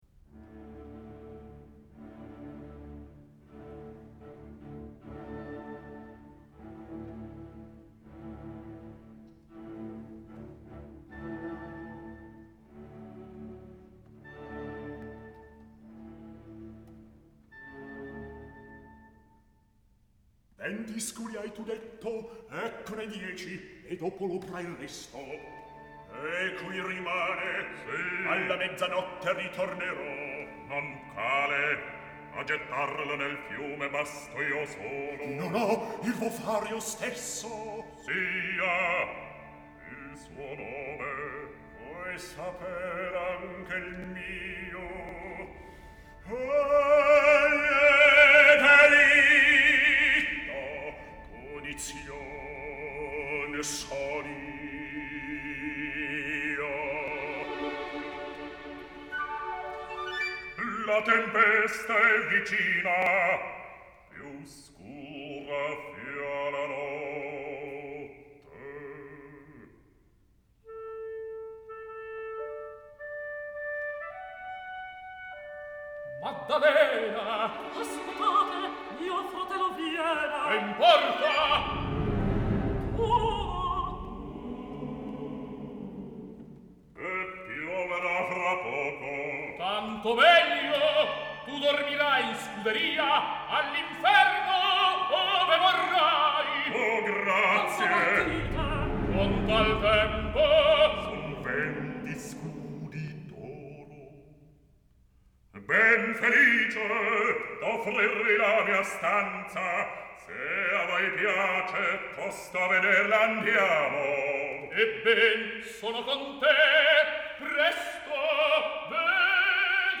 Verdi returns to the recitative idiom here, but it is a recitative punctuated by a variety of orchestral figures alluding to the approaching storm, which become more obtrusive and continuous and finally develop into a miniature tone-poem, the tempesta proper.
In the storm scene the Maestro required the chorus to utter a sort of muffled roar, an indistinct sound through closed lips, accentuated merely by dynamic
uses offstage male chorus a bocca chiusa to depict not only the sound of the wind amid a storm